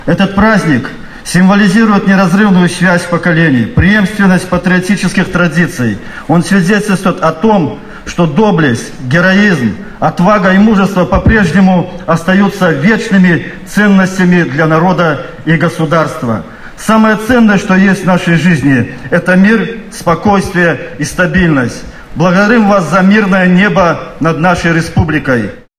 В городском Доме культуры состоялась торжественное мероприятие.
Обеспечить безопасность жителей Беларуси и при этом сохранить миролюбивую политику государства – задача, с которой успешно справляются военные, — отметил замглавы района Михаил Борисевич.